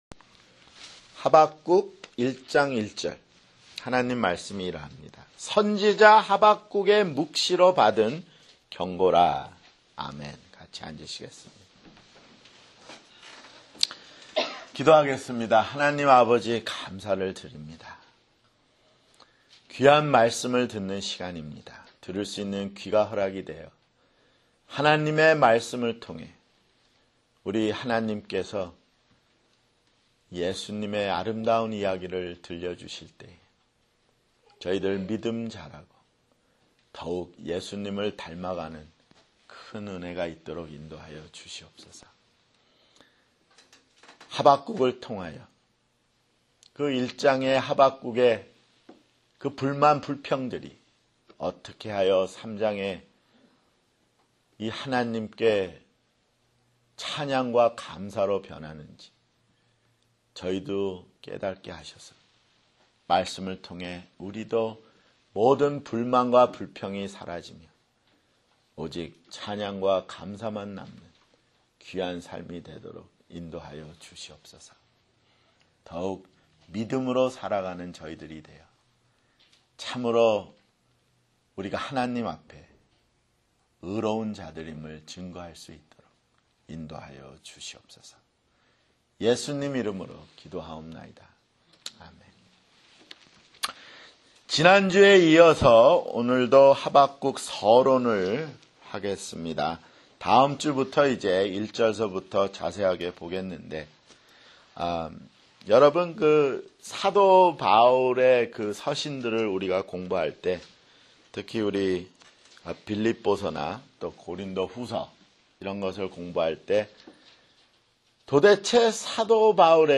[주일설교] 하박국 (2)